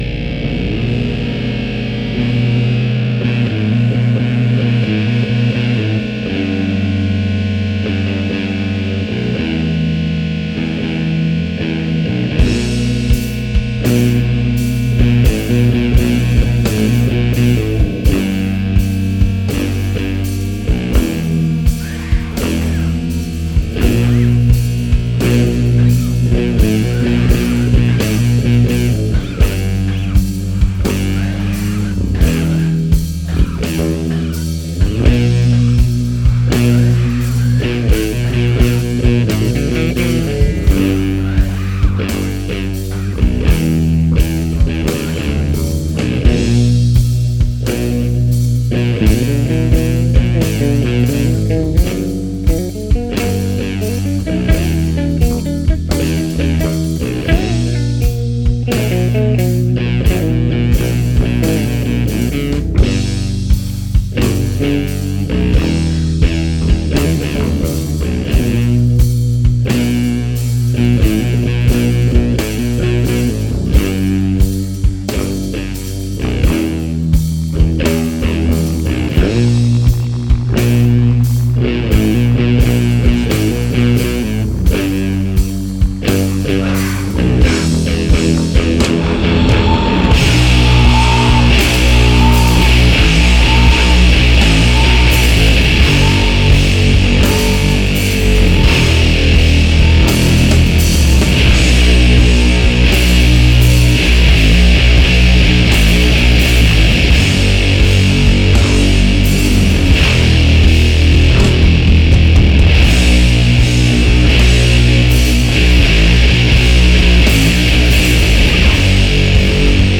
Doom metal